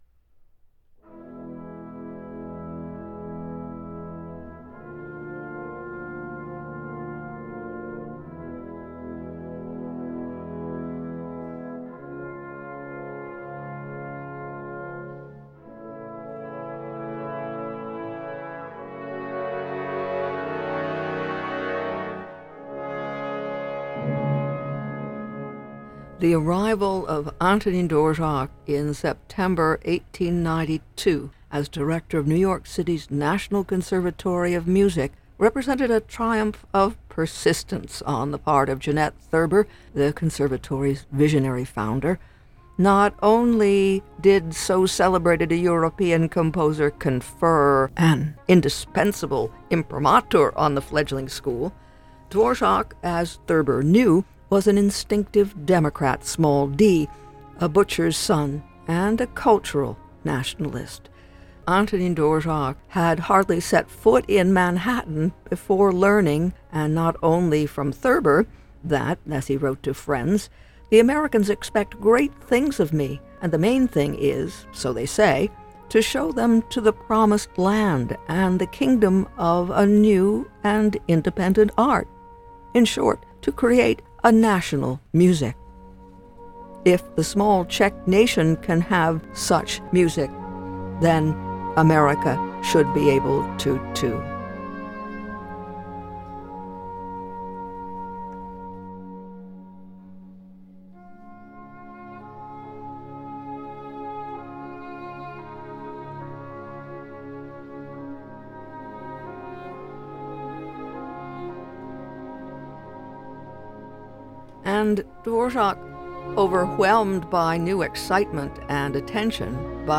Join her weekdays at Noon for interviews, reviews and commentaries on films, books, jazz, and classical music.